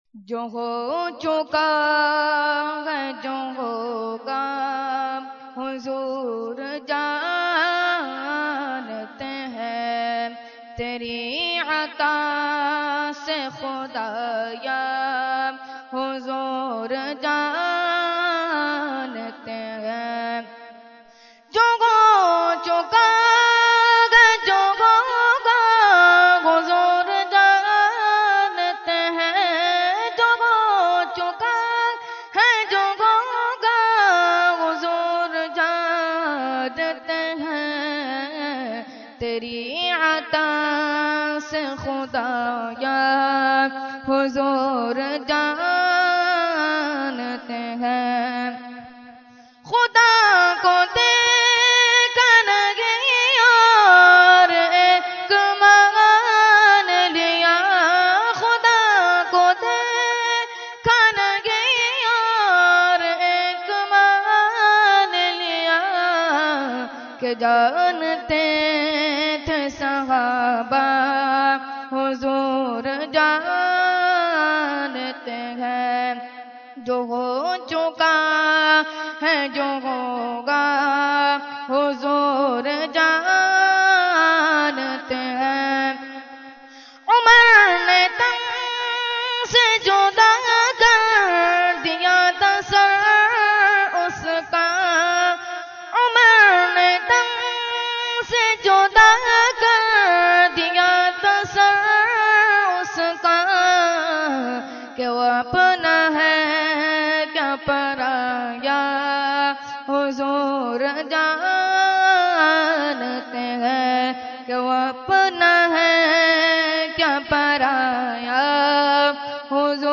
Category : Naat | Language : UrduEvent : Khatmul Quran 2014